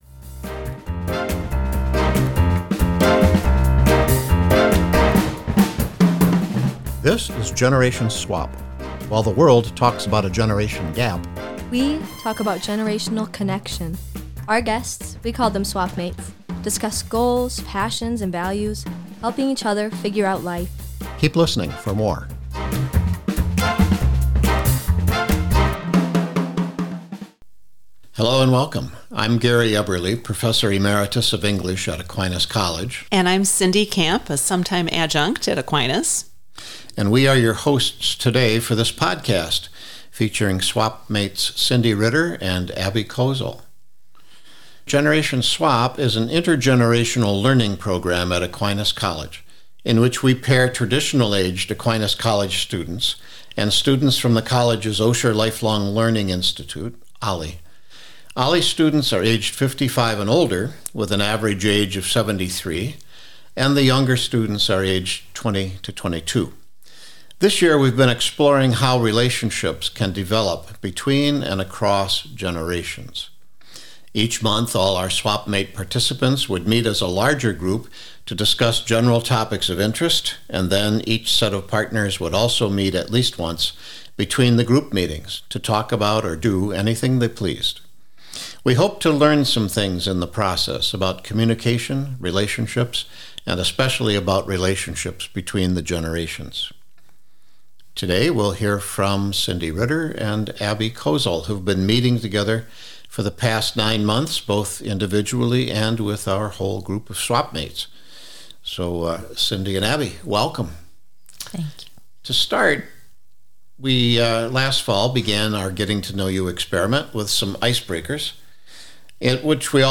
The course capstone project was a Generation Swap podcast interview exploring how generational differences can be a source of strength and growth.